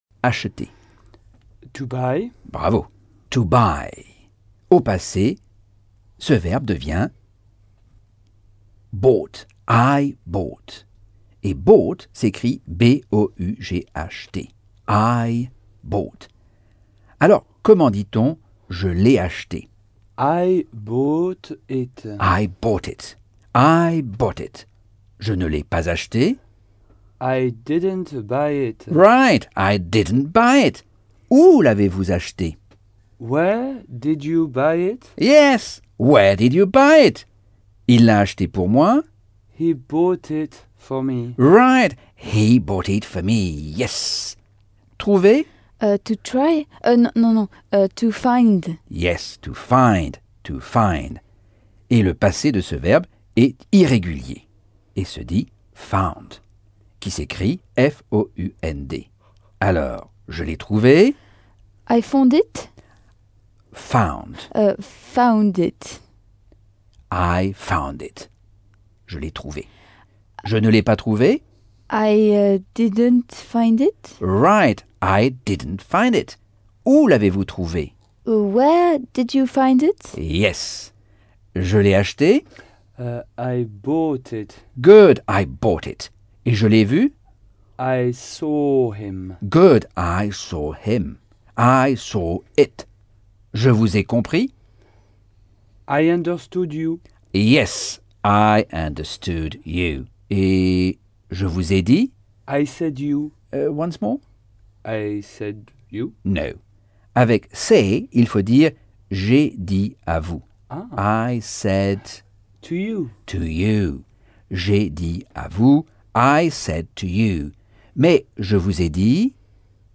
Leçon 3 - Cours audio Anglais par Michel Thomas - Chapitre 7